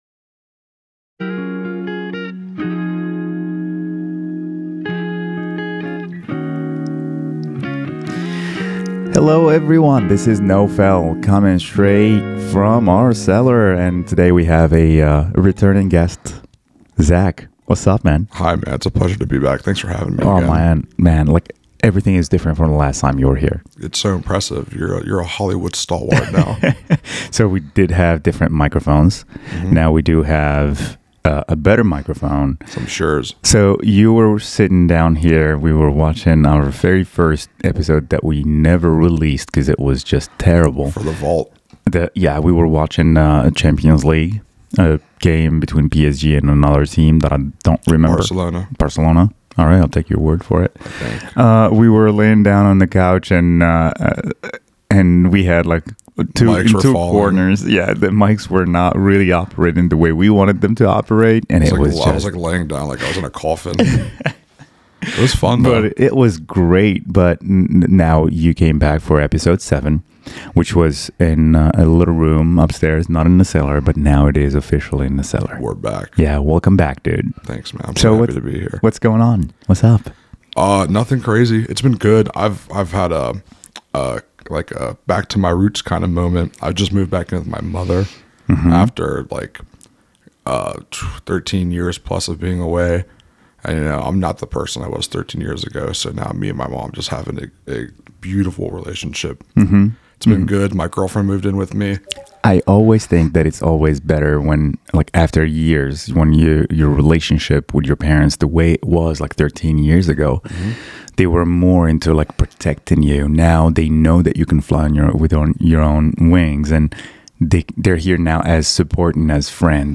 We dive into what’s changed in his life, the challenges he’s faced, and how he found the motivation to reconnect with his creativity. Tune in for an authentic discussion on personal growth, resilience, and the power of music to keep us grounded. Join us in the cellar!